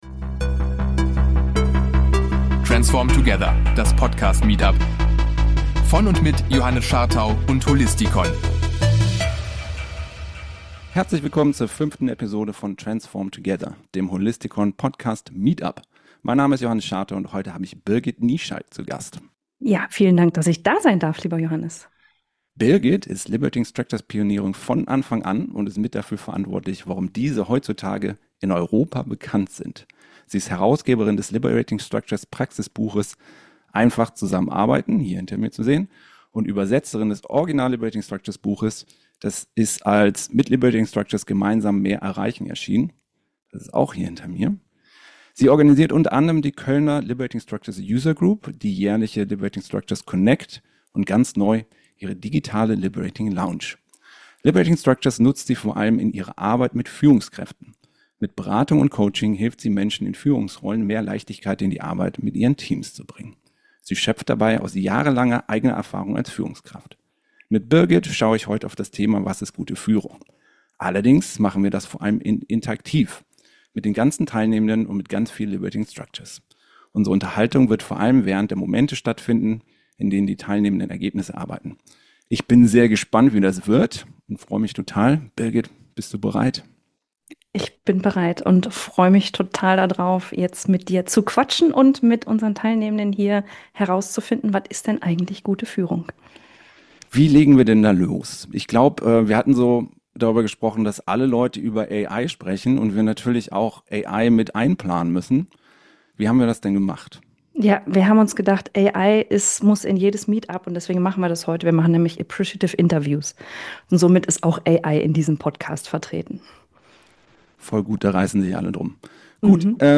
In dieser Episode von transform together wurde es noch interaktiver als sonst.